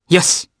Fluss-Vox_Happy4_jp.wav